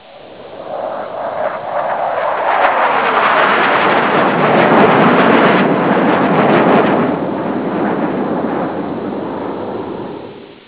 Sound F-16.wav